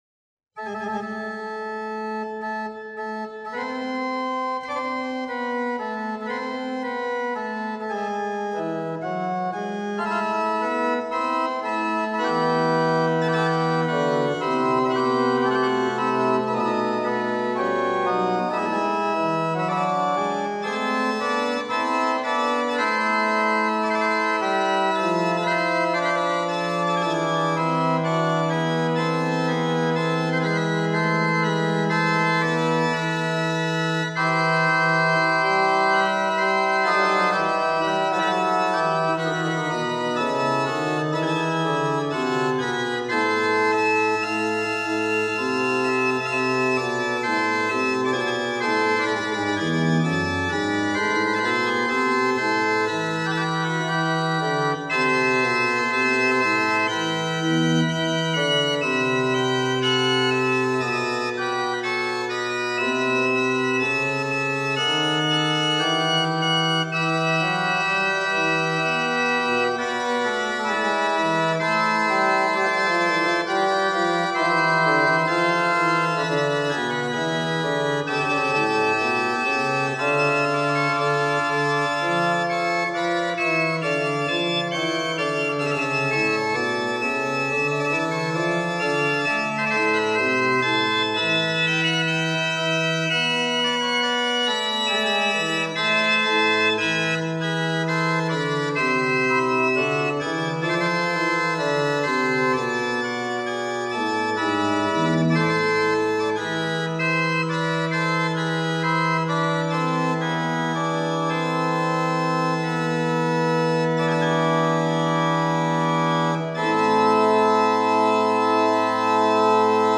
Full Organ